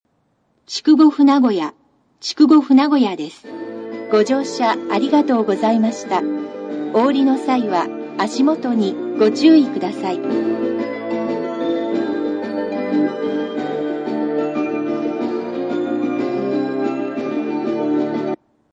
（各詳細ページへ）   発車メロディー スピーカー